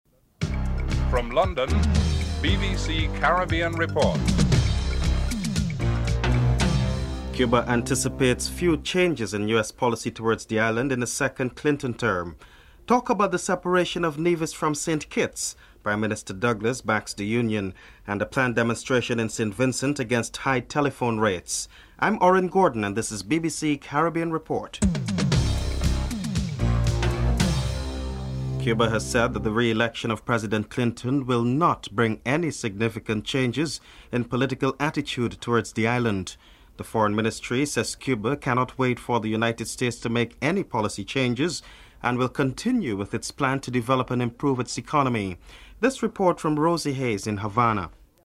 1. Headlines (00:00-00:28)
Prime Minister Denzil Douglas is interviewed (02:24-05:06)